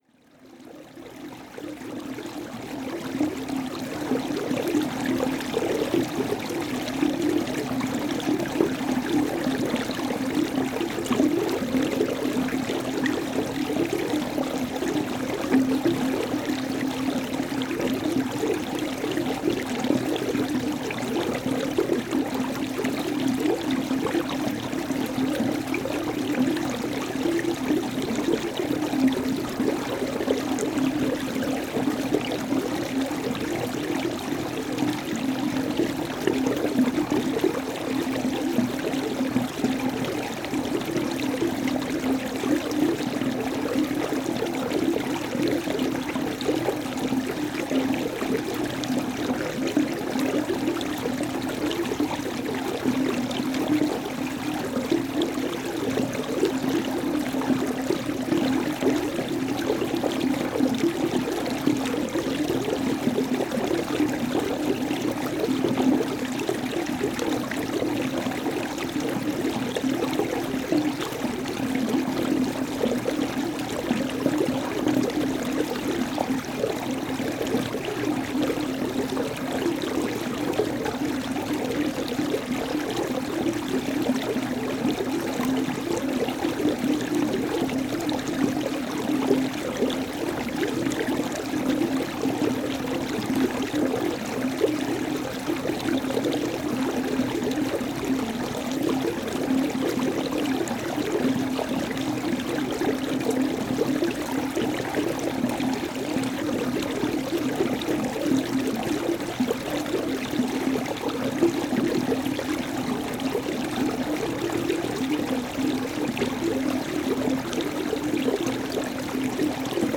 Paisagem sonora de escoamento de água junto à fonte na Rua Largo da Paciência em Zonho, Côta a 11 Março 2016.
A água junto à fonte no Largo da Paciência é fria e ecoa por um tubo.
NODAR.00518 – Côta: Escoamento de água junto à fonte na Rua Largo da Paciência em Zonho